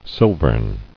[sil·vern]